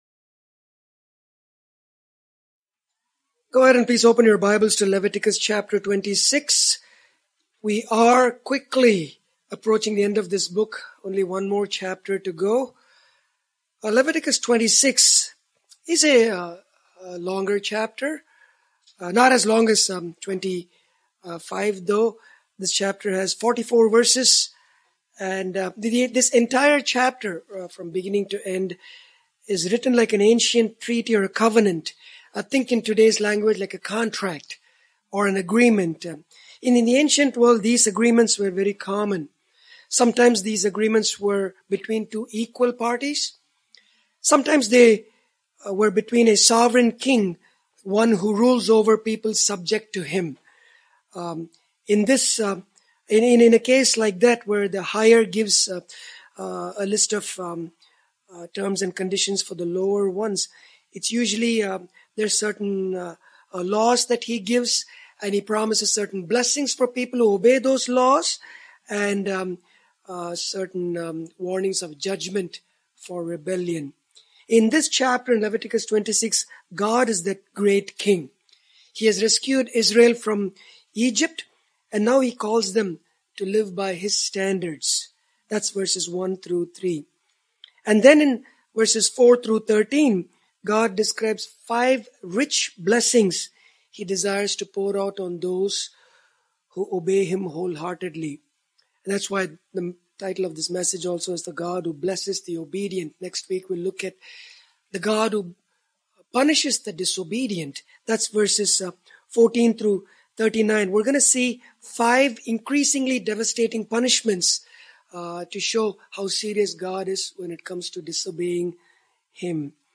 God promised the Israelites five great blessings if they followed His commands. This sermon also explains how these ancient promises apply to believers today who live under the New Covenant established by the Lord Jesus.